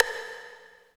20 VERB STIK.wav